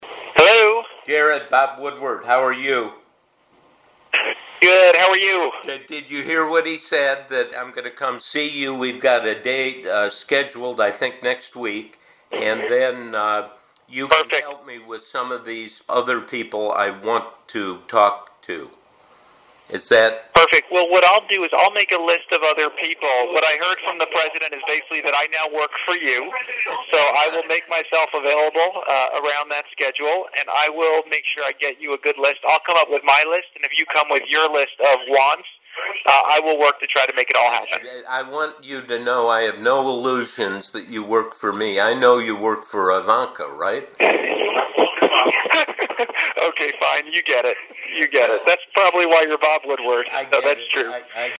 During a call with Woodward in February 2020, Trump hands the phone over to Kushner to set up interviews with other Trump advisers.